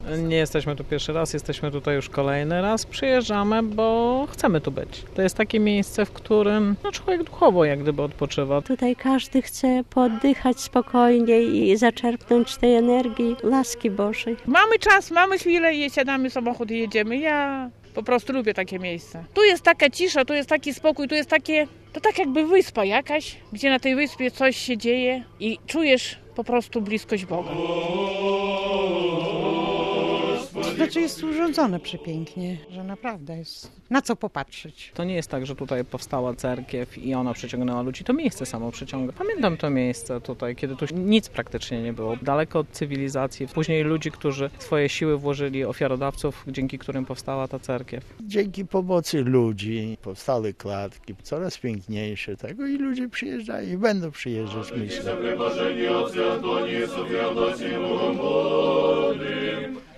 Święto skitu - relacja